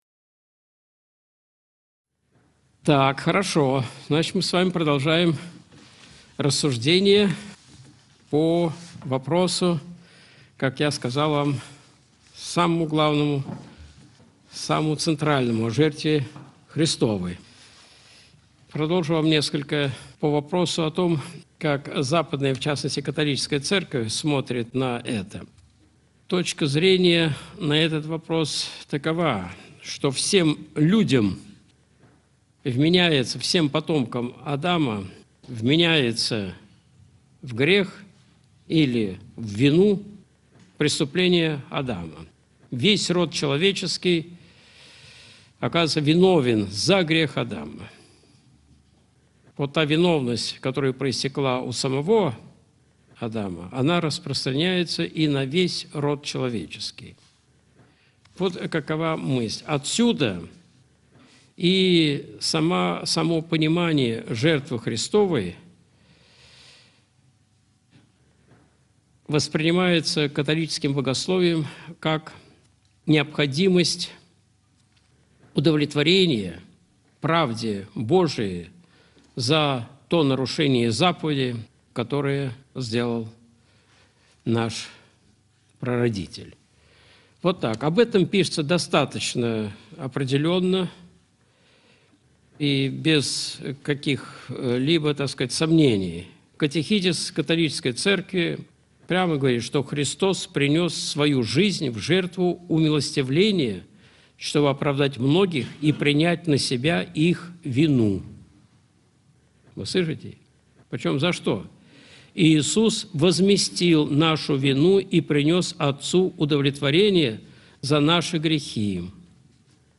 Видеолекции